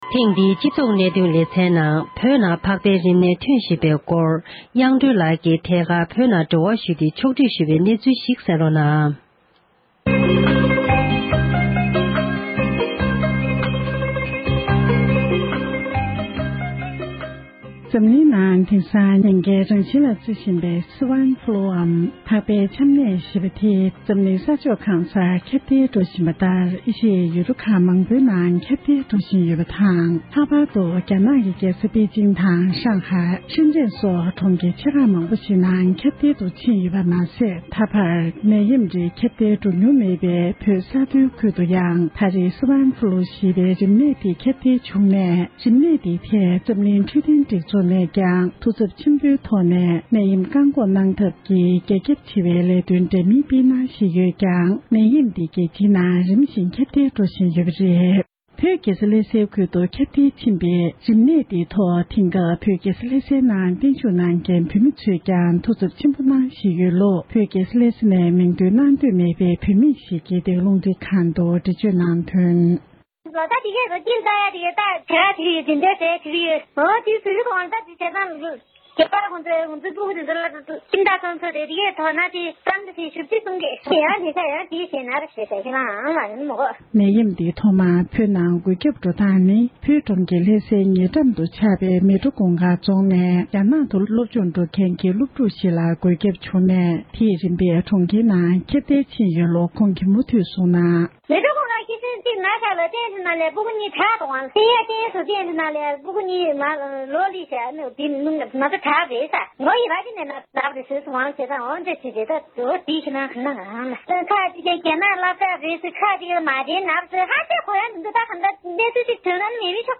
ས་གནས་ཀྱི་འབྲེལ་ཡོད་སྨན་པ་ཁག་ཅིག་ལ་རིམས་ནད་དེ་སྔོན་འགོག་བྱ་ཕྱོགས་སྐོར་གནས་འདྲི་ཞུས་པར་གསན་རོགས༎